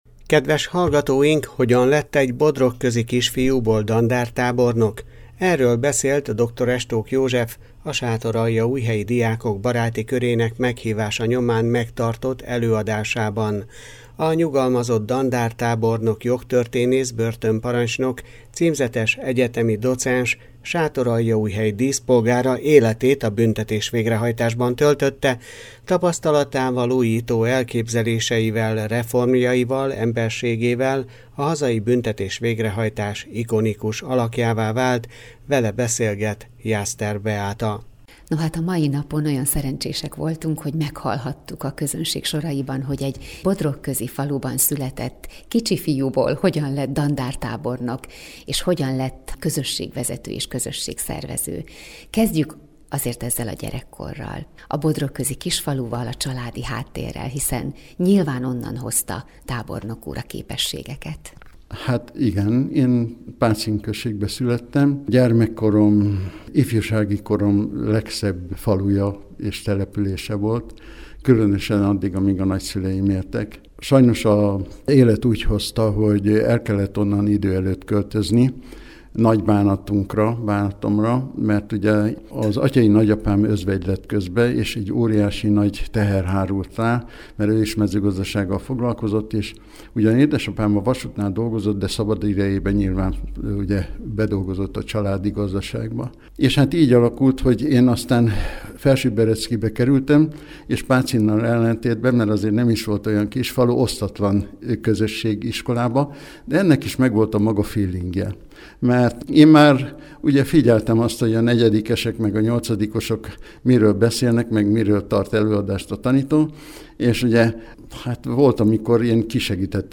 a Sátoraljaújhelyi Diákok Baráti Körének meghívása nyomán megtarott előadásában